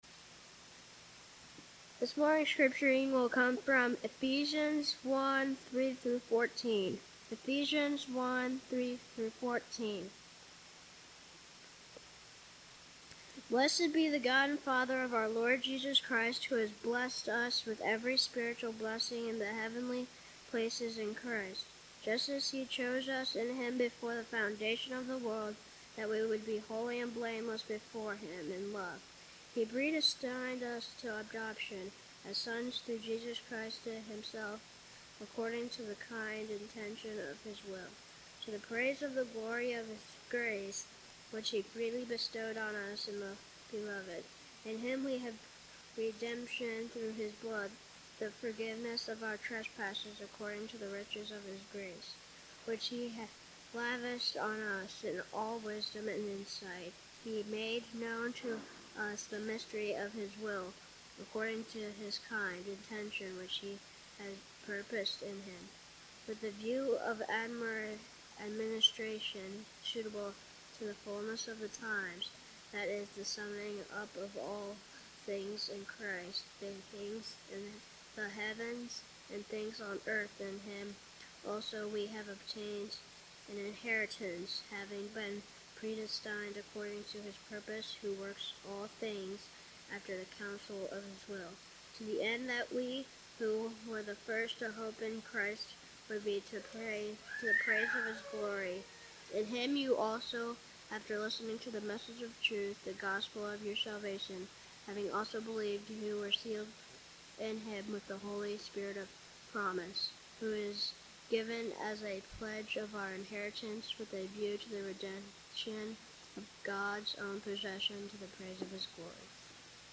Passage: Ephesians 1:3-14 Service: Sunday Morning Topics